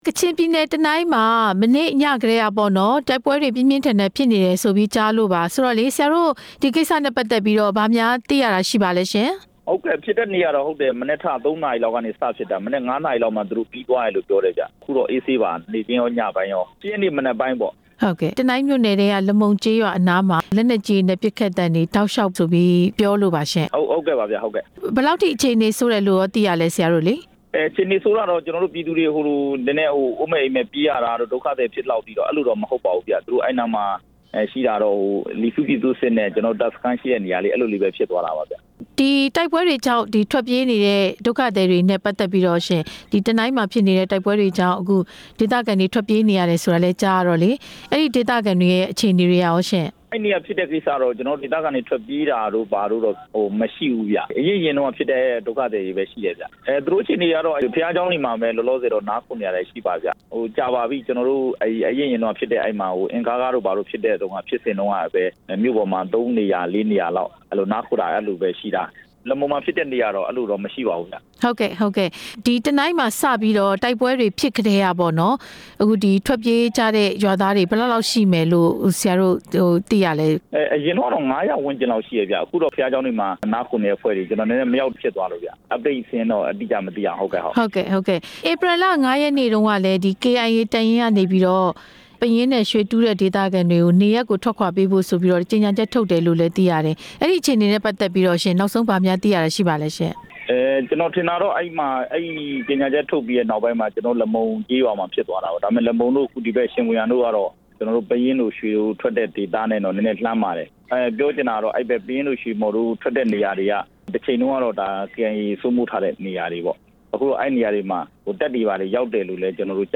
တနိုင်းတိုက်ပွဲအကြောင်း လွှတ်တော်ကိုယ်စားလှယ် ဦးဇော်ဝင်းနဲ့ မေးမြန်းချက်